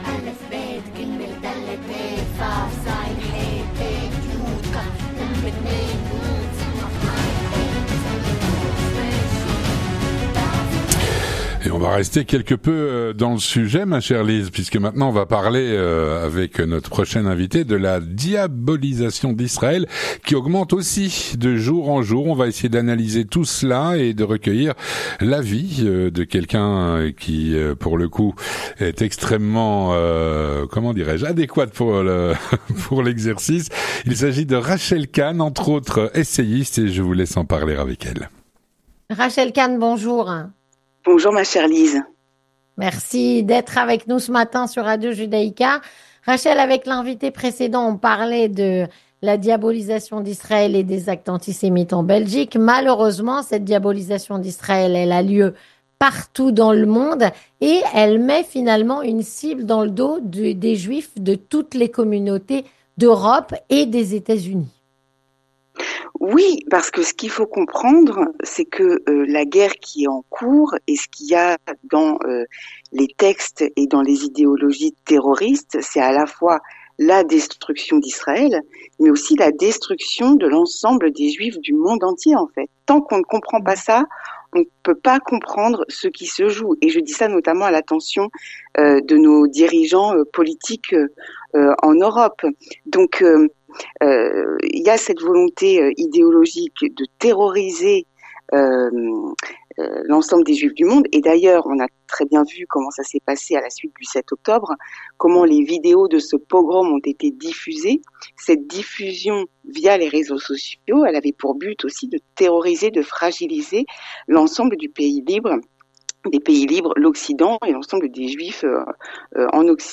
L'analyse de Rachel Khan, essayiste, qui nous fait le plaisir d'être sur notre antenne ce matin.